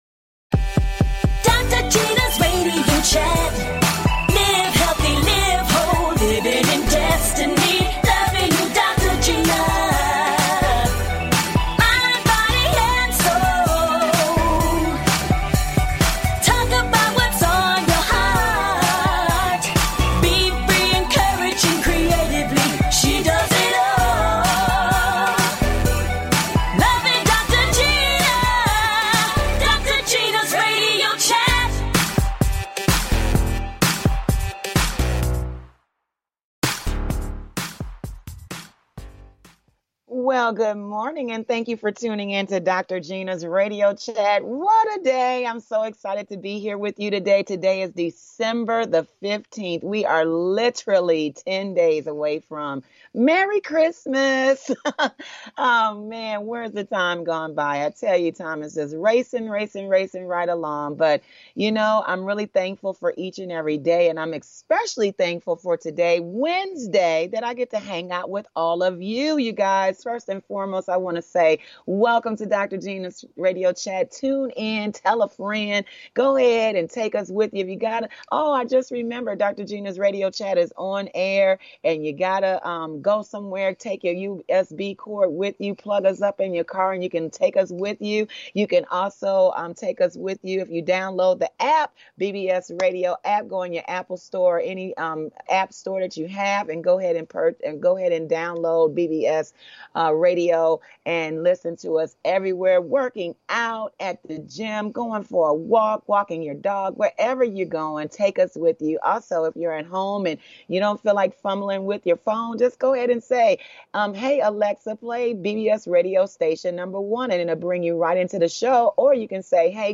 Talk Show Episode, Audio Podcast
And full of laughter!
A talk show of encouragement.